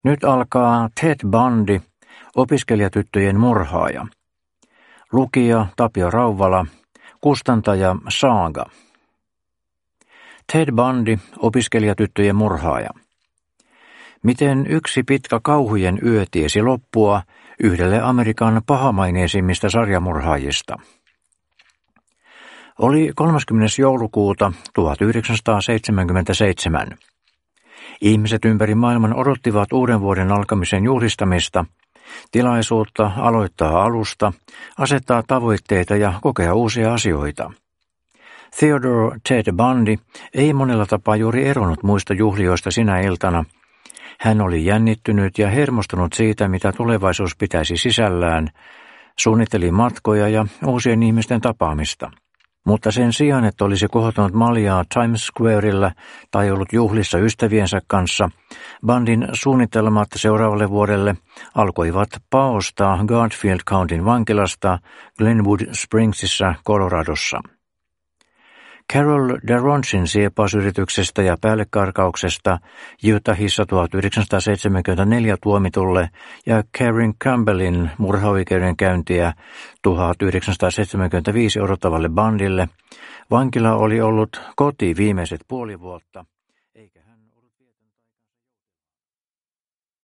Ted Bundy – opiskelijatyttöjen murhaaja (ljudbok) av Orage